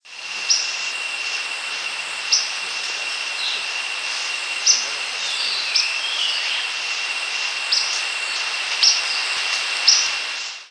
Yellow-bellied Flycatcher Empidonax flaviventris
Flight call description An emphatic, downward-arched "wseew" is presumed to be the flight call.
"Wseew" call from perched bird.
"Wseew" call from perched bird with Spot-breasted Wren singing in the background.
Similar species "Wseew" call very similar to some variations of Acadian Flycatcher's "pseet" but is typically higher, less rising, and less emphatic.